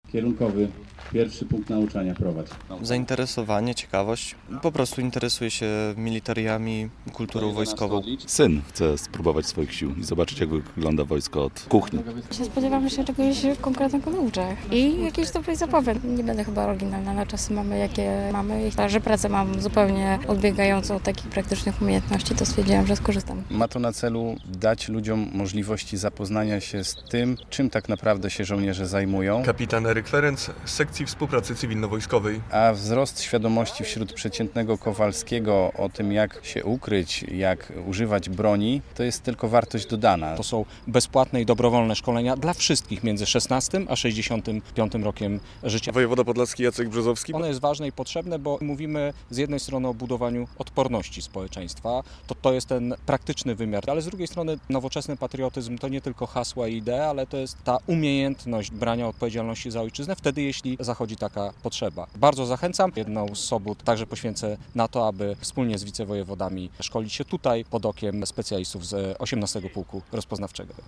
"Trenuj z wojskiem" w Białymstoku - relacja